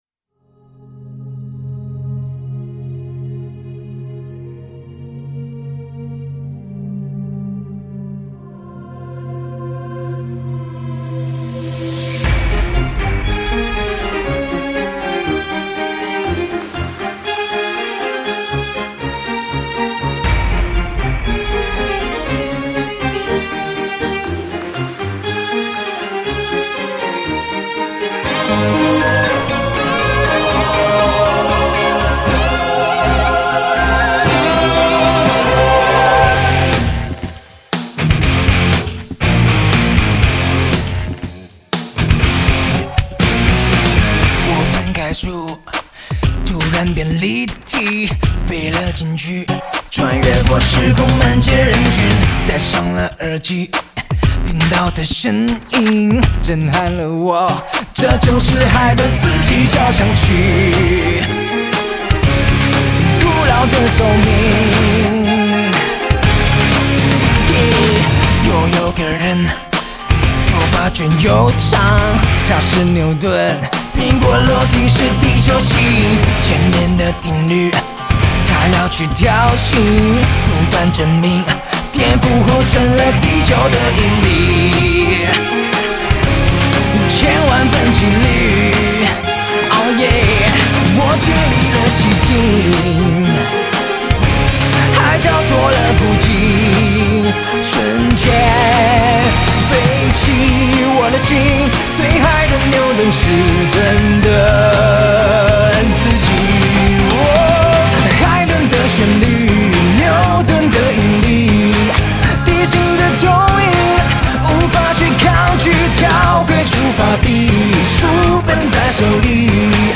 富有感染力和穿透力的嗓音